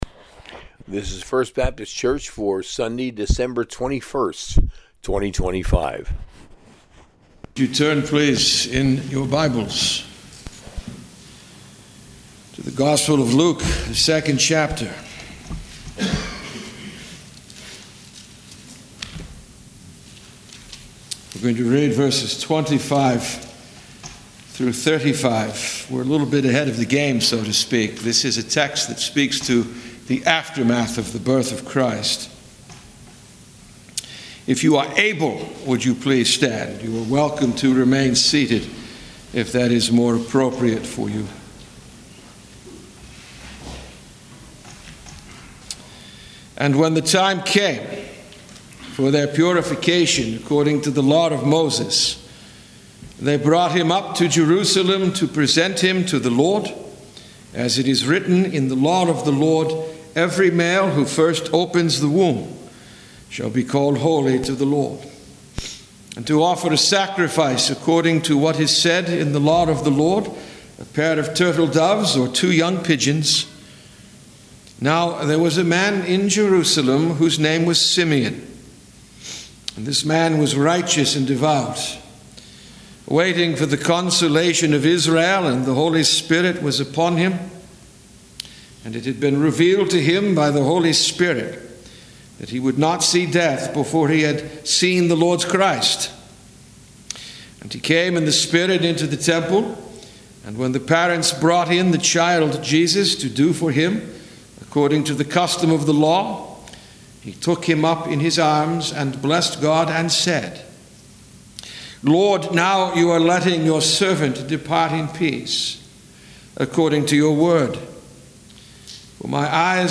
An Exposition through Luke 2;25-35